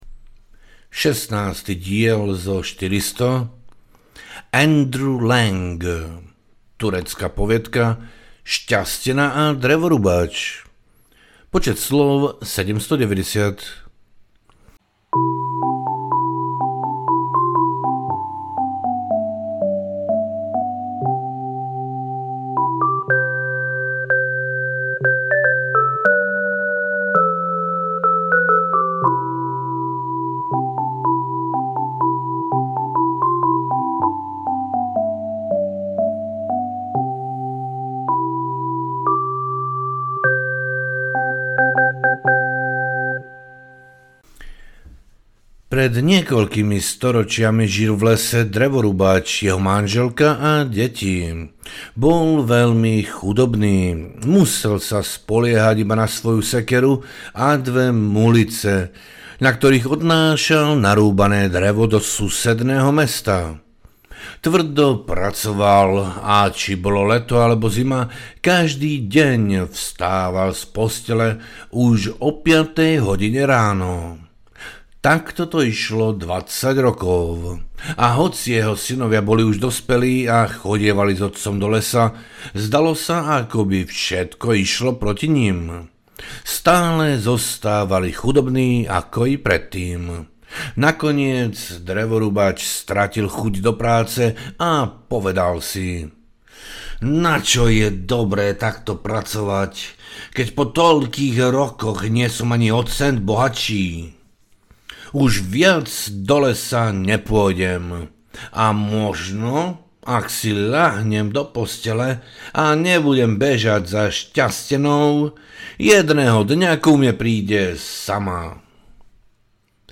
100+1 poviedok na večery a noci audiokniha
Ukázka z knihy